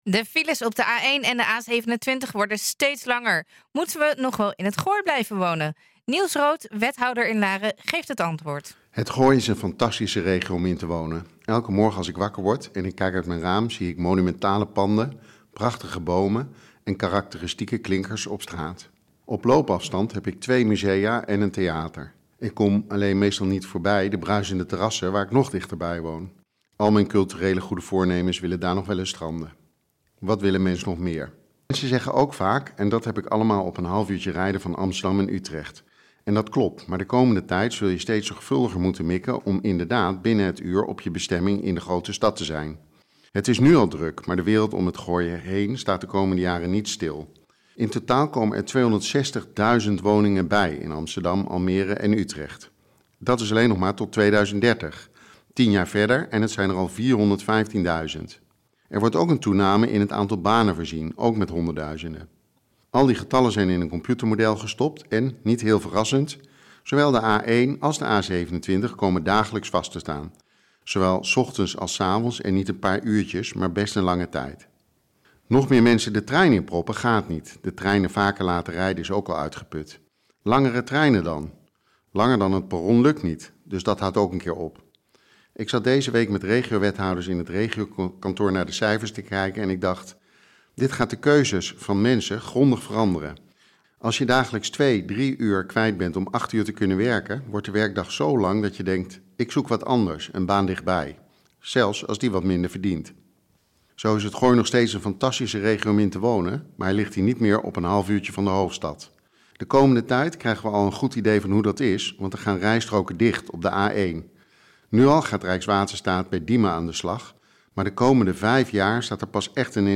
De files op de A1 en de A27 worden steeds langer. Moeten we nog wel in het Gooi blijven wonen? Niels Rood, wethouder in Laren, geeft het antwoord en oplossingen in zijn column in NH Gooi Zaterdag (zaterdag 17 mei 2025)
NH Gooi Zaterdag - Column door Niels Rood
nh-gooi-zaterdag-column-door-niels-rood.mp3